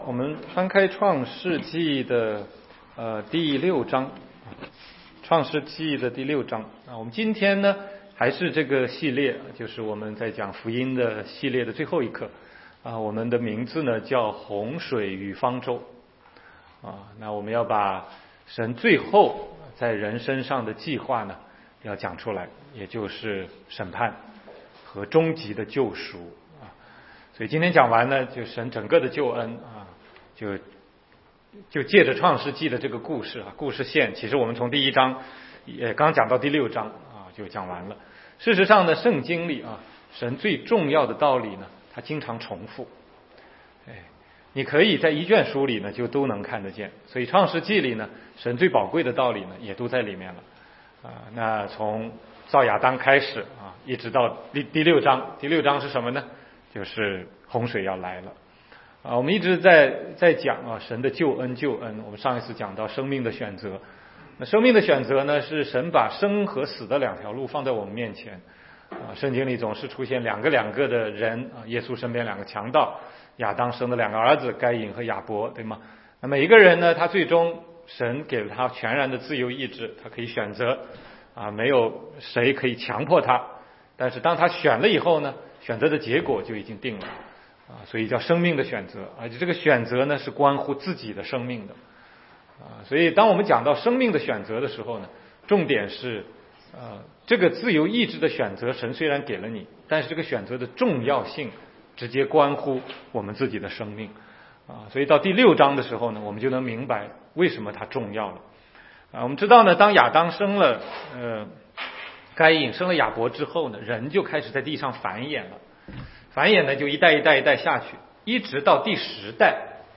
16街讲道录音 - 洪水与方舟